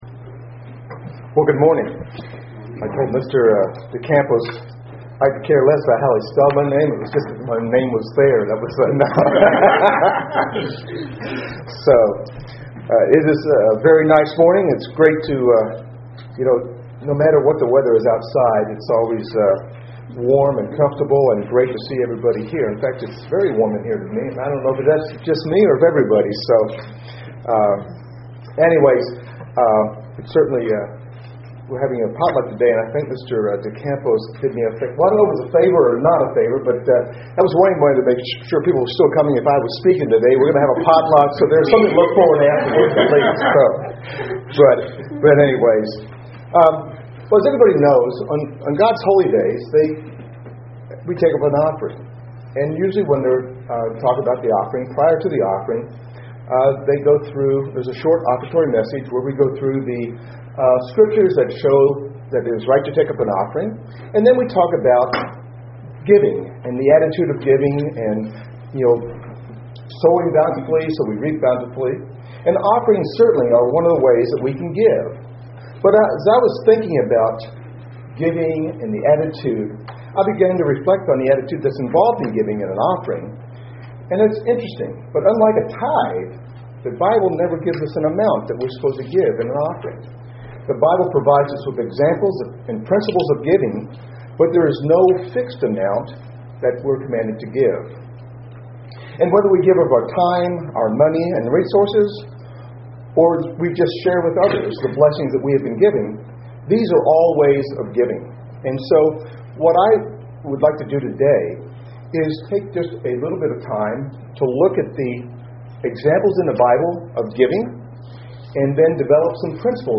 Print Principles of equality, sowing bountifully and giving to your enemies UCG Sermon Studying the bible?